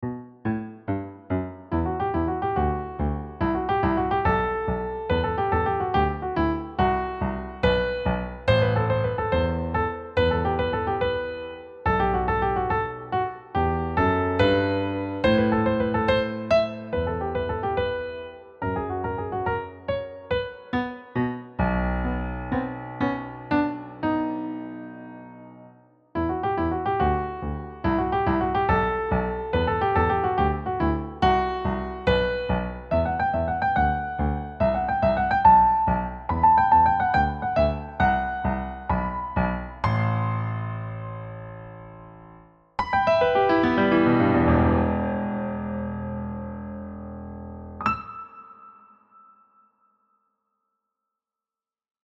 Key: E minor with a few accidentals
Time Signature: 6/8 (quick and driving)